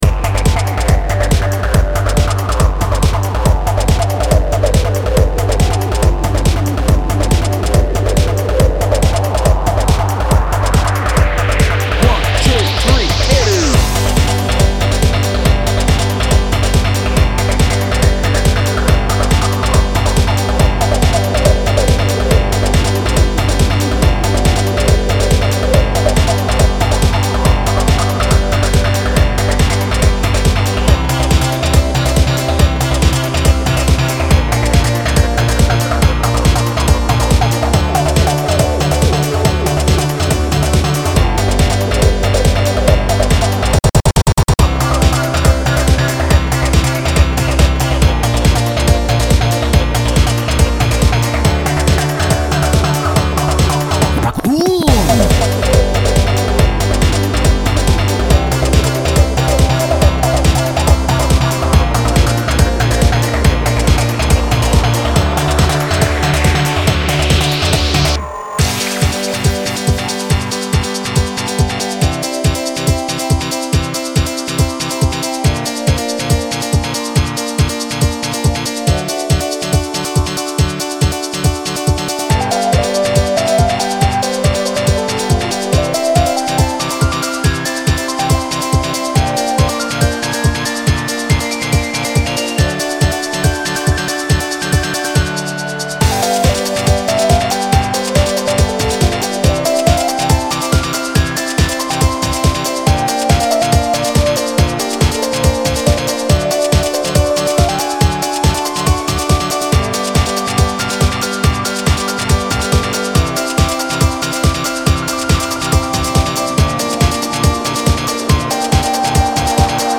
Produced at the party using OpenMPT and the Synth1 VST, along with various samples I have lying around on my laptop.